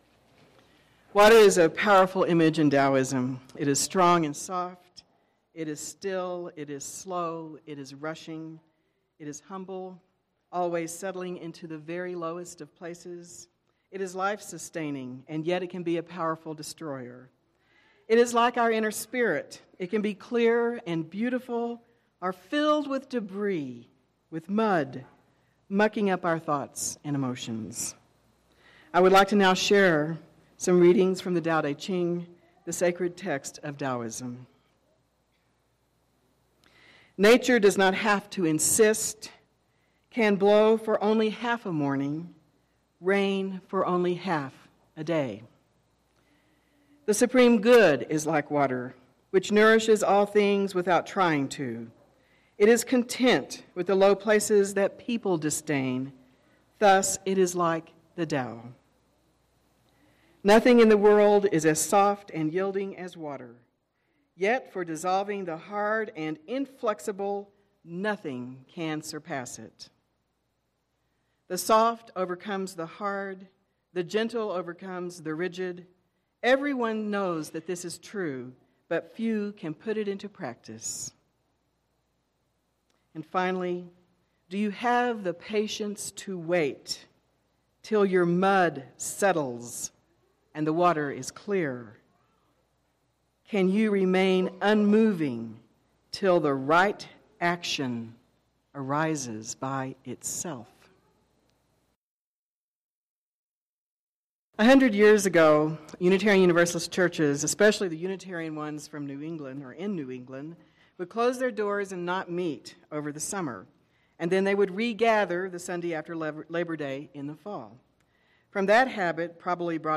Fall Water Communion Service